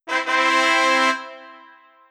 fanfare.wav